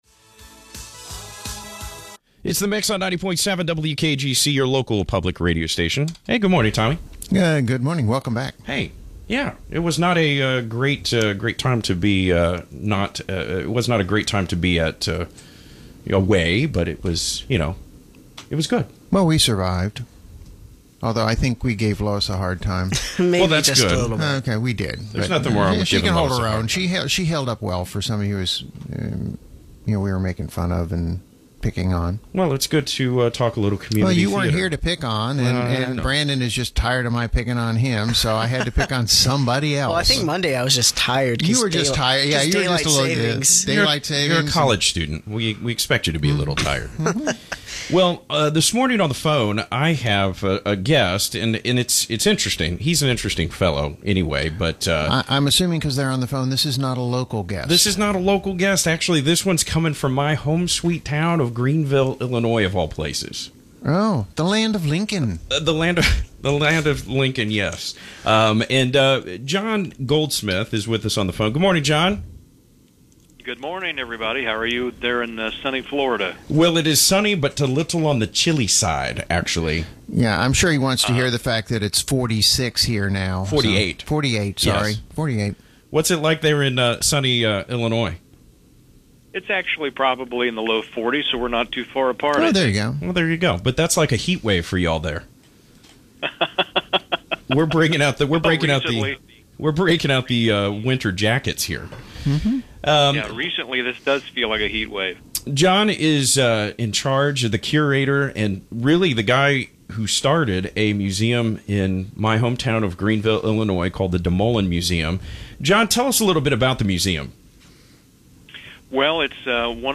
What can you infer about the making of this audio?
There was a Vinyl Play and more on The Morning Mix!